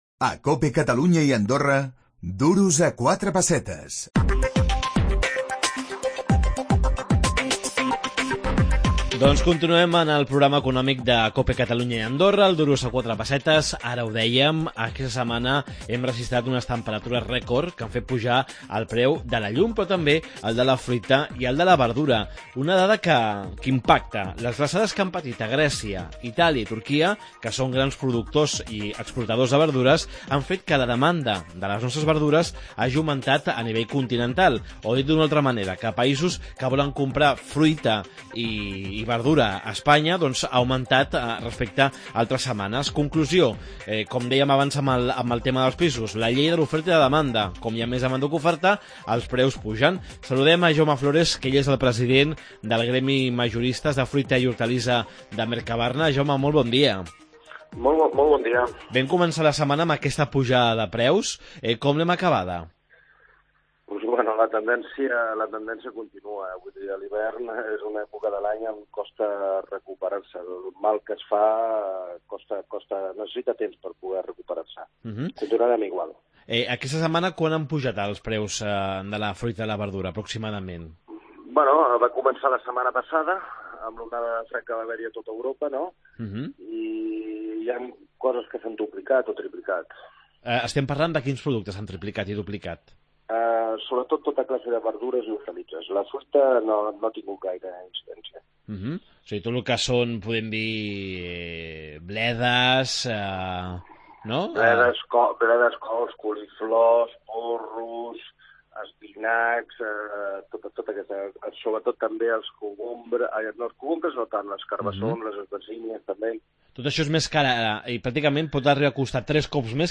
El preu de la verdura s'ha doblat i fins i tot triplicat aquesta setmana pel fred. Entrevista